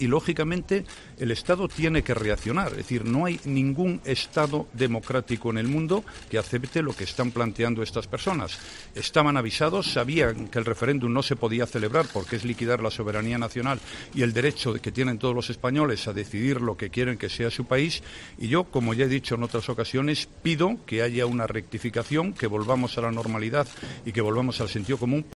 Rajoy ha hecho esta declaración en los pasillos del Congreso, tras contestar a preguntas de la oposición en el pleno de control, donde ha tenido un encontronazo con el portavoz adjunto de ERC, Gabriel Rufián, quien ha llegado a pedir al presidente que saque "sus sucias manos" de las instituciones catalanas.